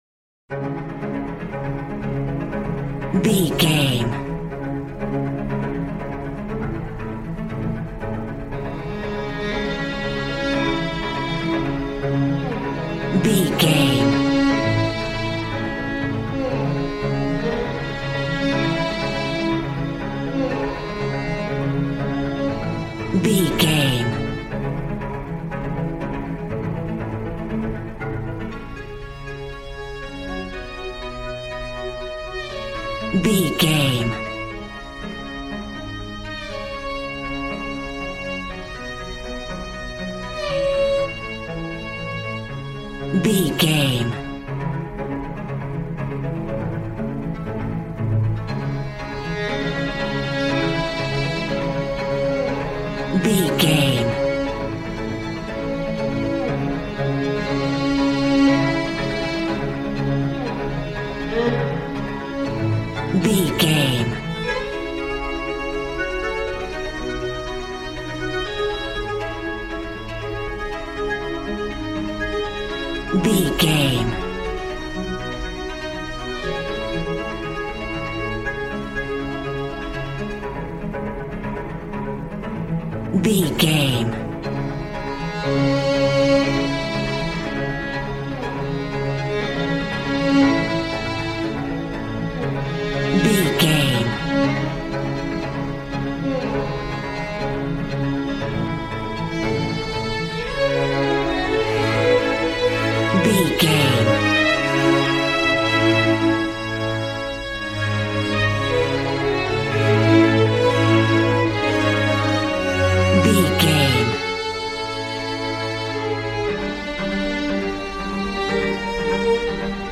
Modern film strings for romantic love themes.
Regal and romantic, a classy piece of classical music.
Ionian/Major
regal
cello
violin
brass